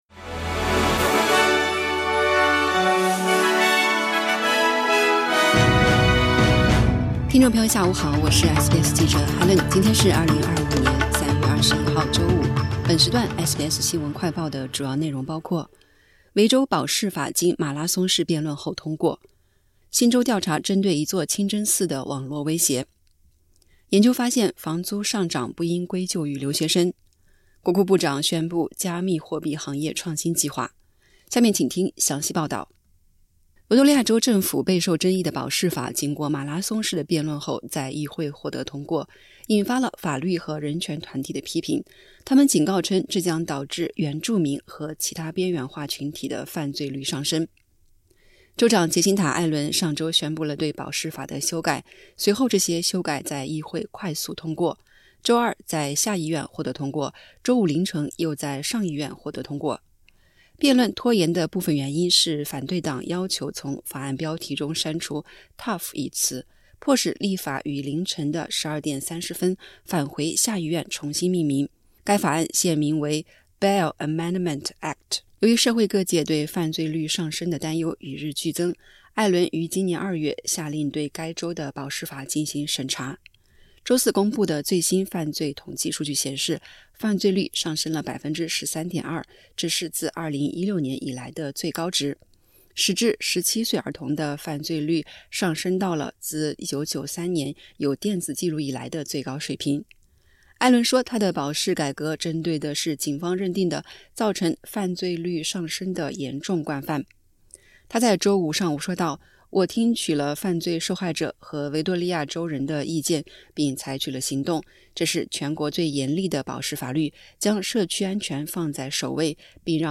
【SBS新闻快报】维州保释法通过 最新公布的犯罪率上升13.2%